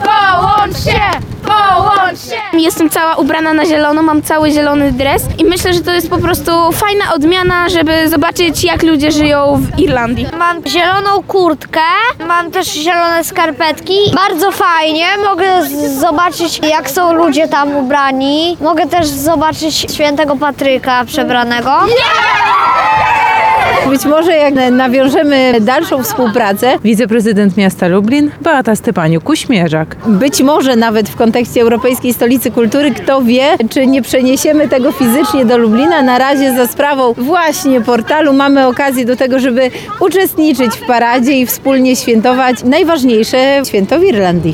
Sytuację na miejscu obserwowała nasza reporterka.